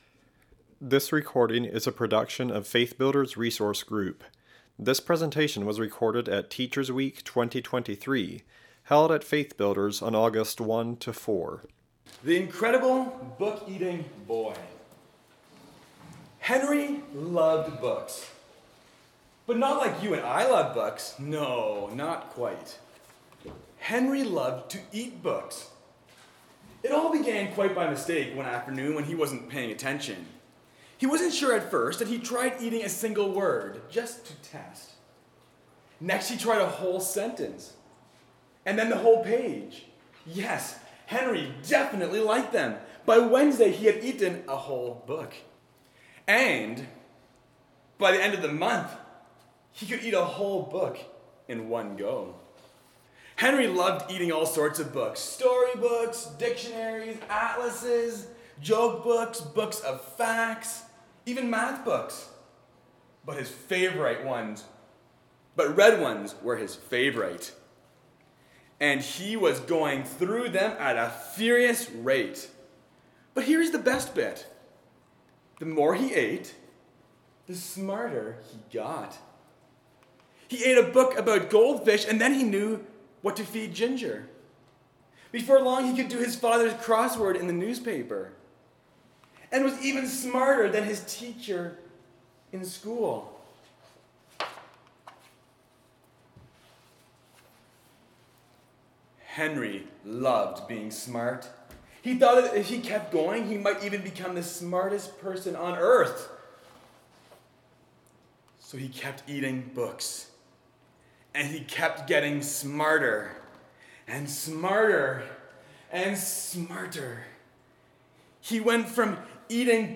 Home » Lectures » Developing a Passion for Reading
In this session we will discuss ways to develop a positive reading atmosphere in lower grade elementary classrooms. This session will be led by a teacher with eight years of experience teaching first and second grade as well as three years teaching fifth and sixth grade.